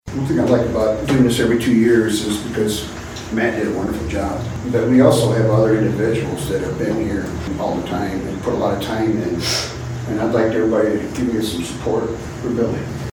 During Monday’s City Council meeting, Larry McConn, Bill Rinehart, and Rick Johannes were sworn in after winning re-election to the Council in the November 5 General Election.
Johannes then made a nomination.